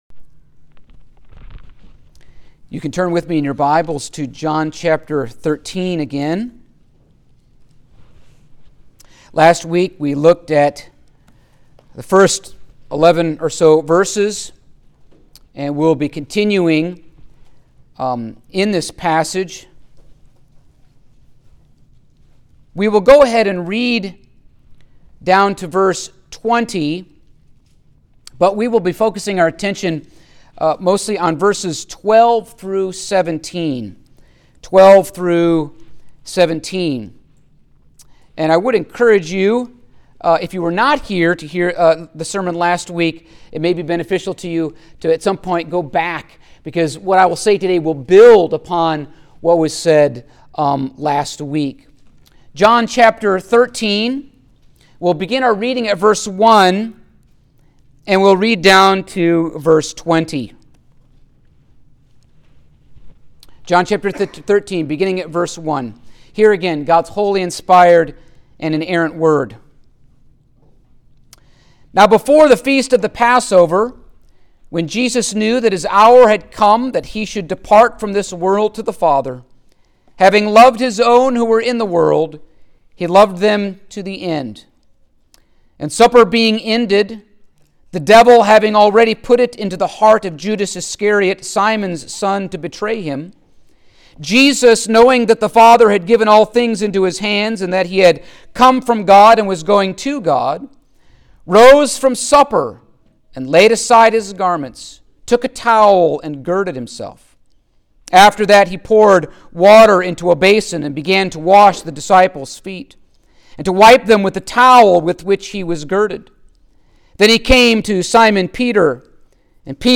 Passage: John 13:12-17 Service Type: Sunday Morning